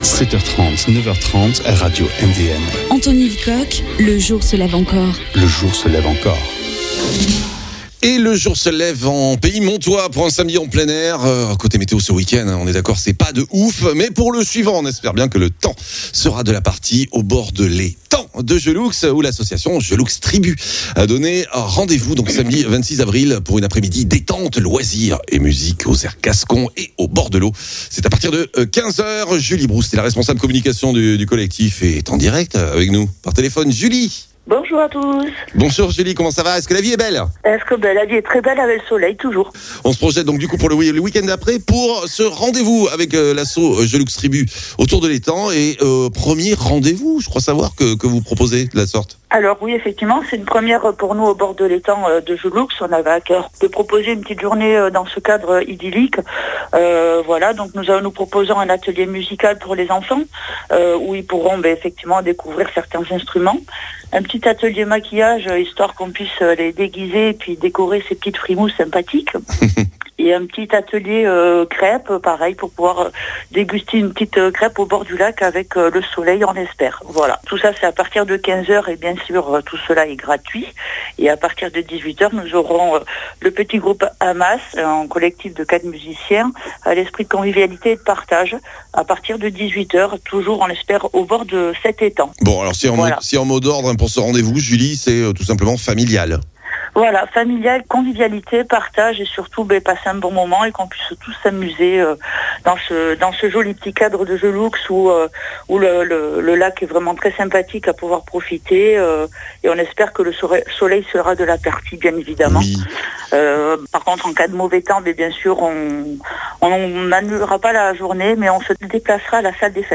Causerie au bord de ‘l’eau